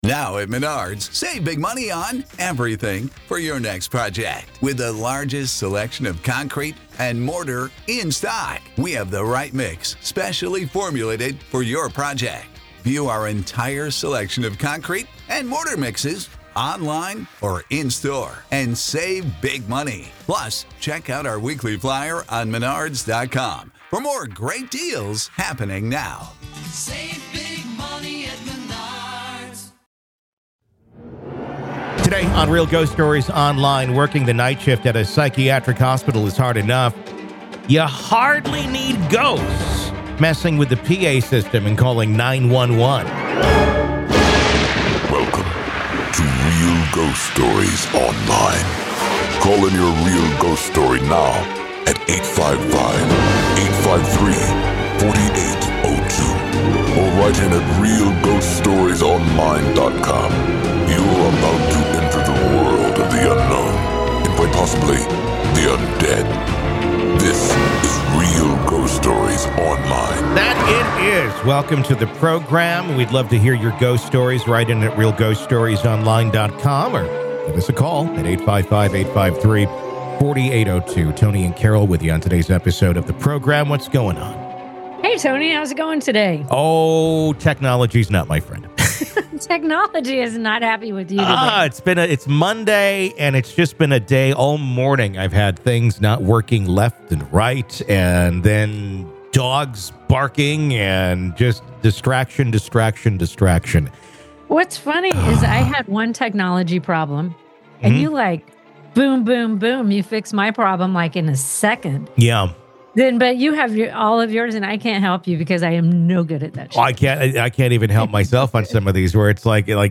Our guest shares unsettling events like mysterious 911 calls from an unused art room, where a spectral girl named "Satana" cries for help.